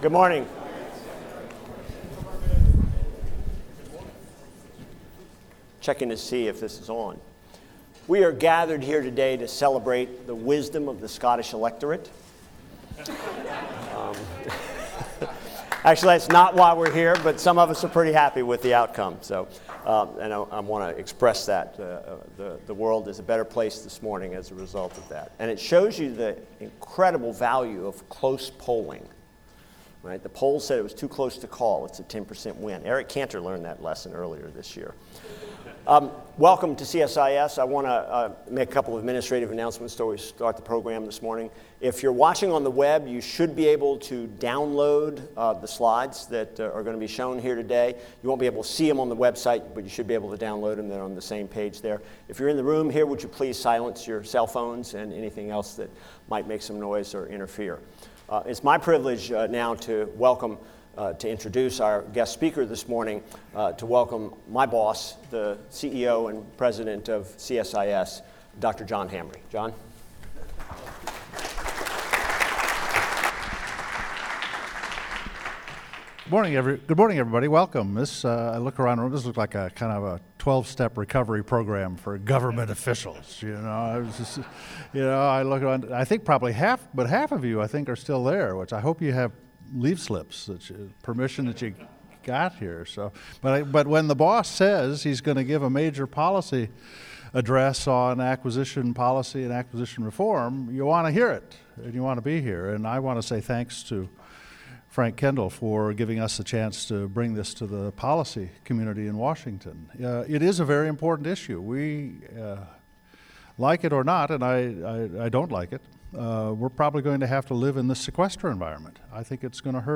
Better Buying Power 3.0 - A Discussion with Under Secretary Frank Kendall | CSIS Events